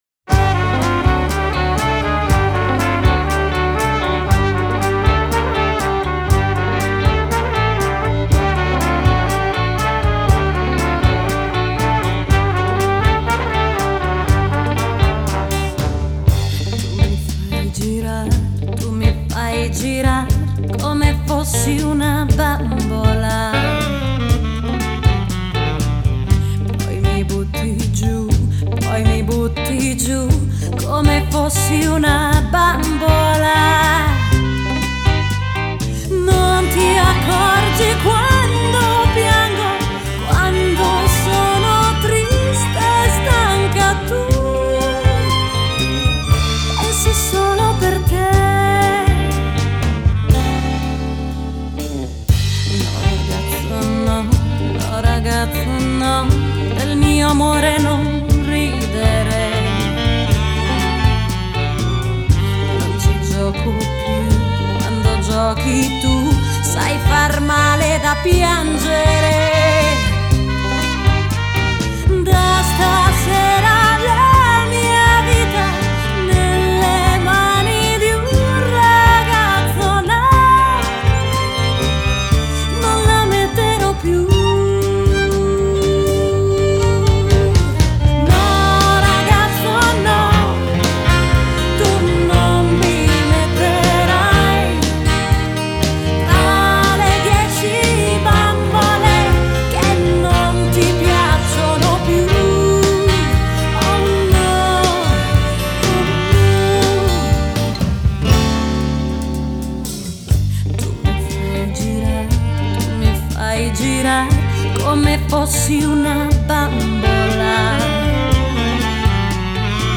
Revival ‘50 ‘60 Italia America